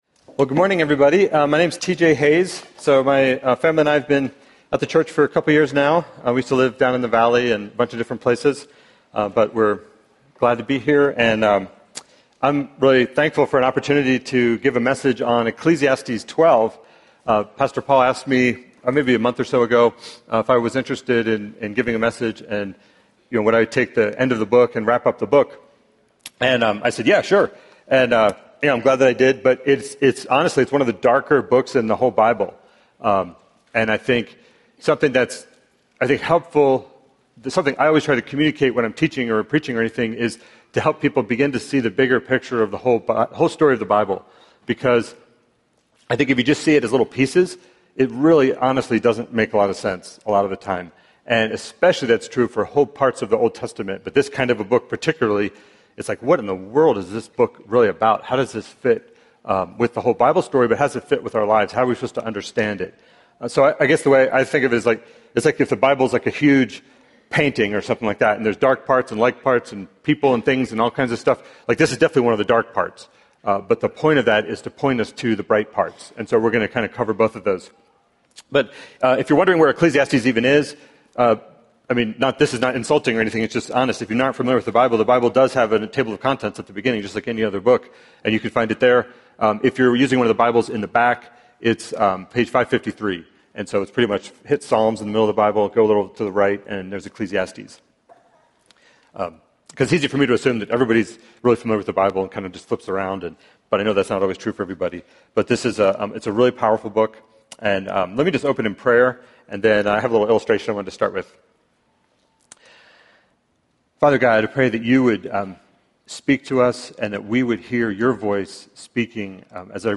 Ecclesiastes - A Meaningful Life - Sermon